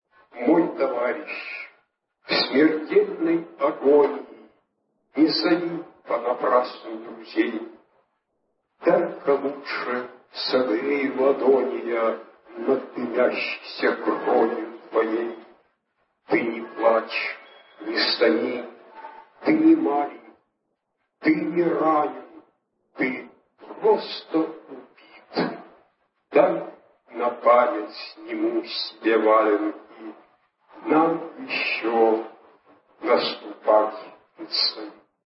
Спустя 70 лет Ион Деген вновь читает своё знаменитое стихотворение: